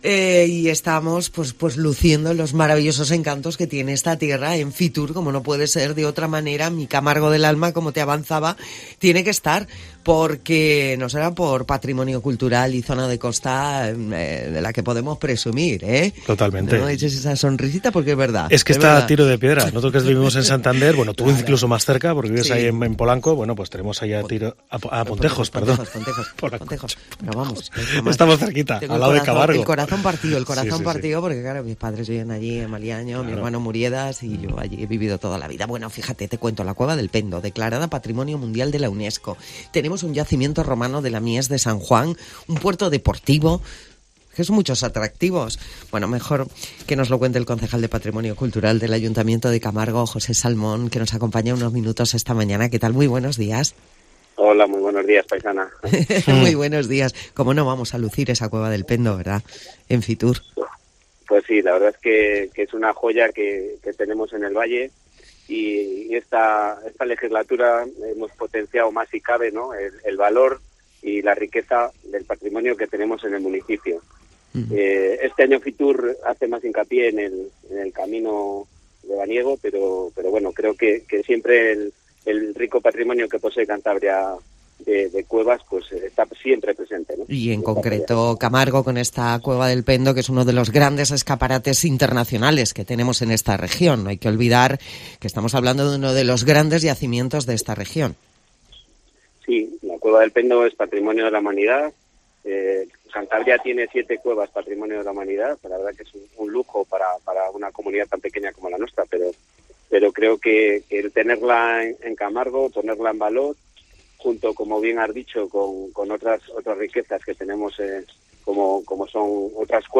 Escucha a José Salmón, concejal de patrimonio cultural del Ayto. de Camargo, hablar de la Cueva de El Pendo